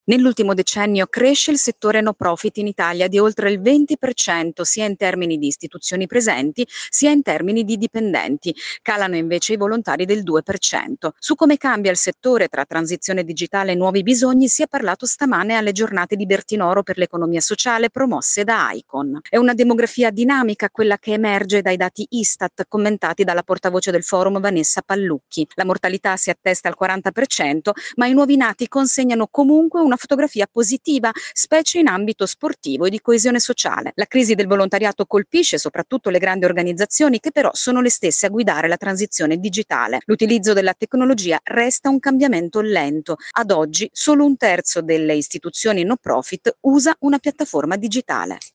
Bentornati all’ascolto del Grs Week.